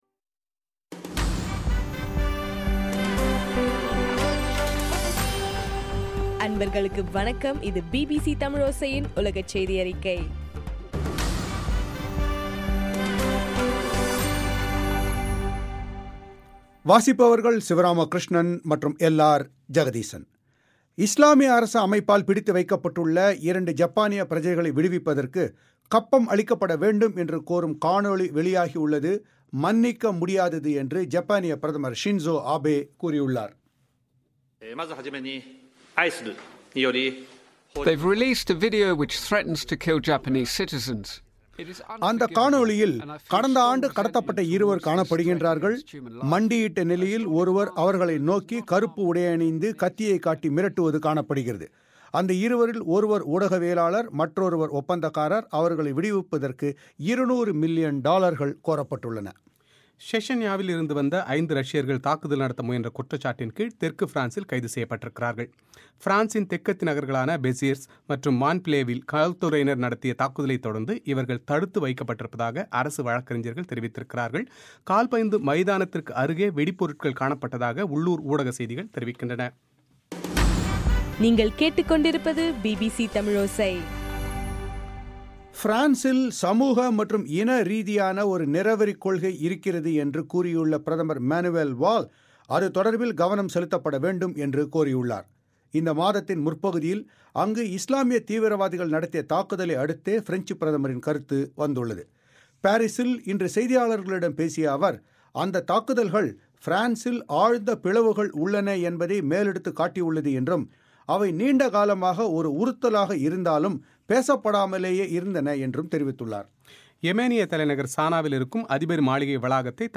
ஜனவரி 20 பிபிசியின் உலகச் செய்திகள்